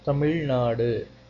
Tamil Nadu (/ˌtæmɪl ˈnɑːd/; Tamil: [ˈtamiɻ ˈnaːɽɯ]
Tamil_Nadu.ogg.mp3